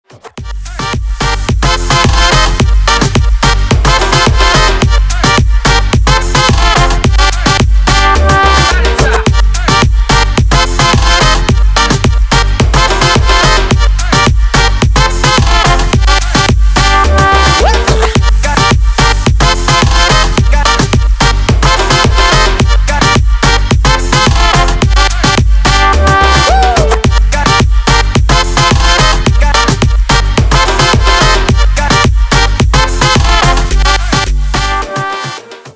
• Качество: 320, Stereo
ритмичные
громкие
зажигательные
dance
Moombahton
труба
Trumpets
заводная игра на трубе под мумбатон